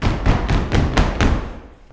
121 STOMP4-L.wav